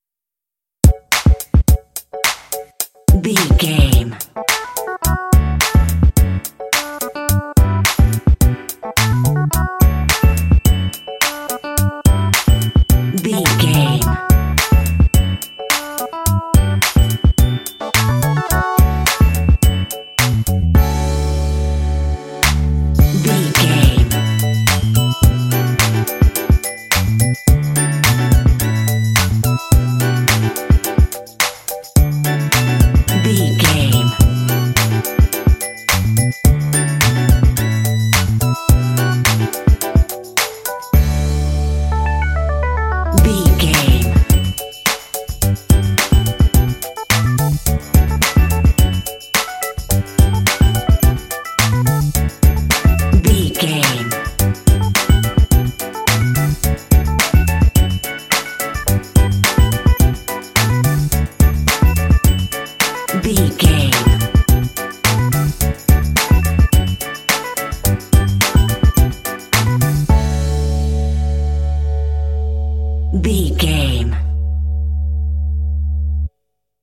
Uplifting
Ionian/Major
funky
happy
bouncy
groovy
electric guitar
bass guitar
synthesiser
drums
Funk